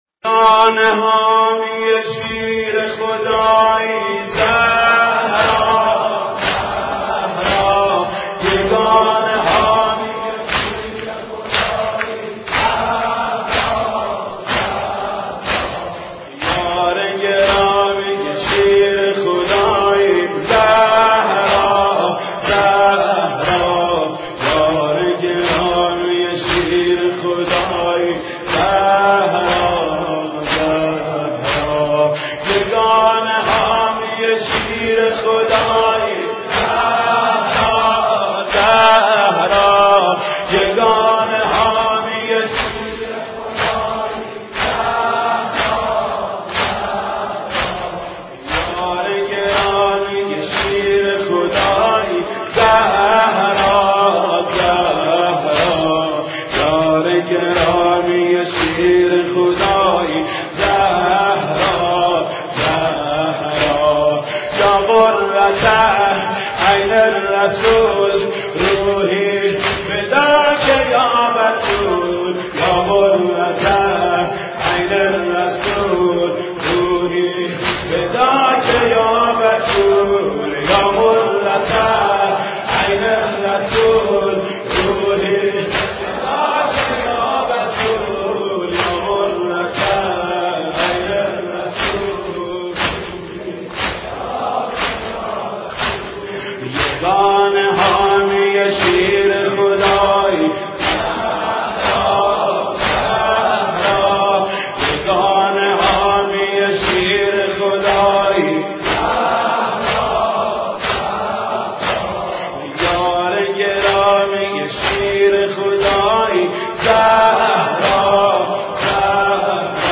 مداحی حاج محمود کریمی به مناسبت ایام سوگواری حضرت زهرا(س) (5:52)